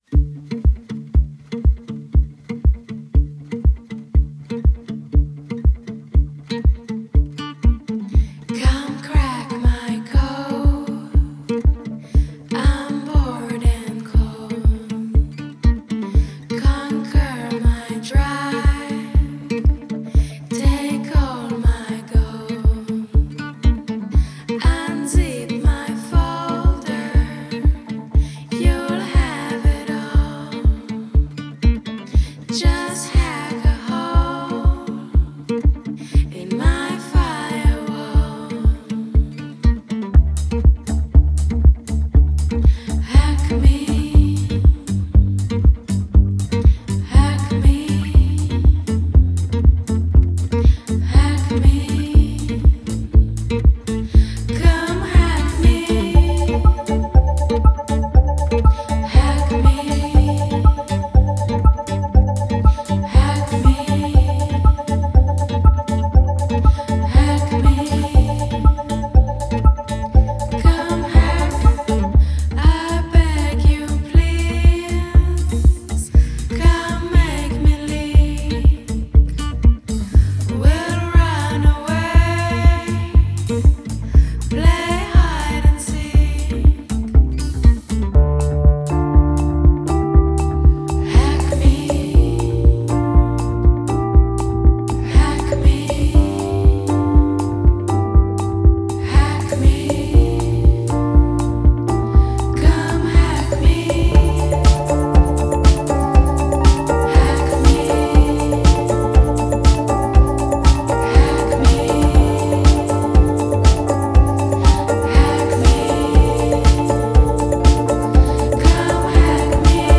Genre: Pop, Pop Rock, Synth